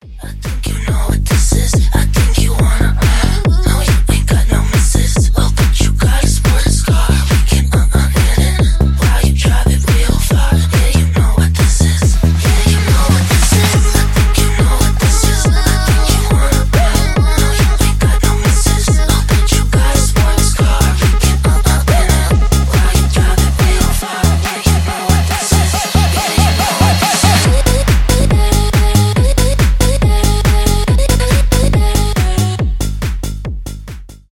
техно
транс , tech house